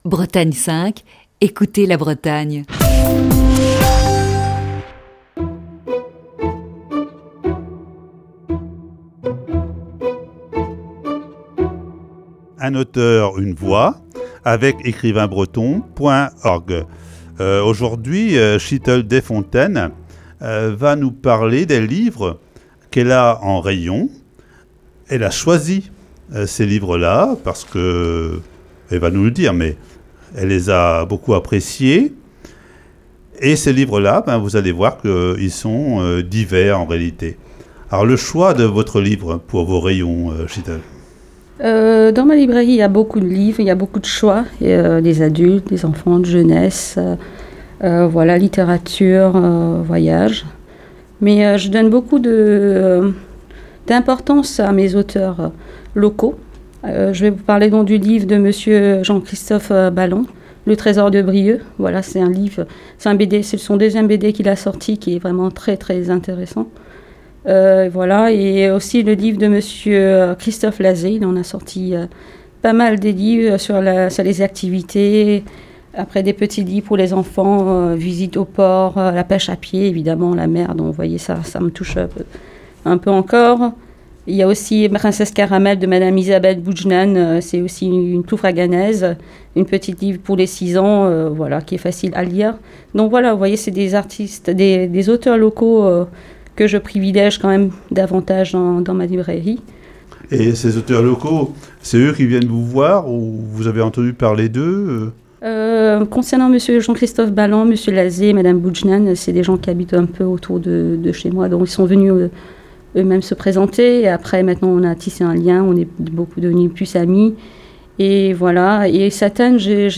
entretien diffusé le 6 février 2020.